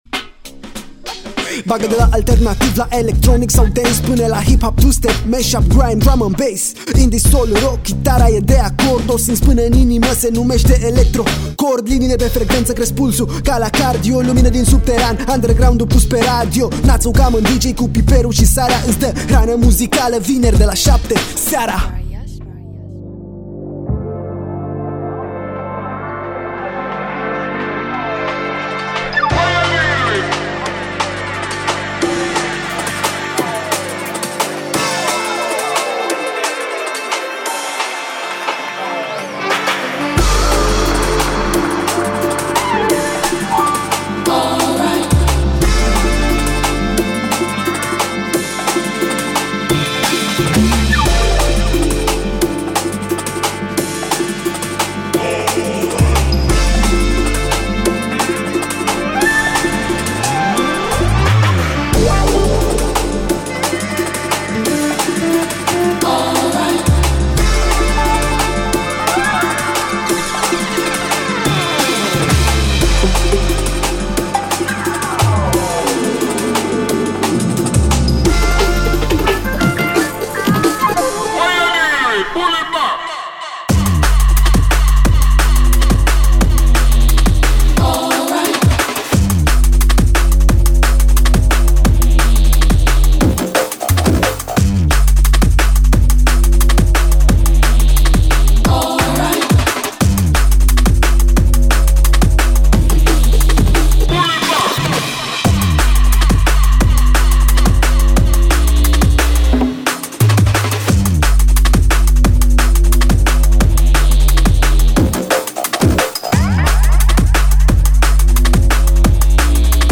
muzicii electronice de dans (EDM)
un mix de piese numai potrivite pentru începutul de weekend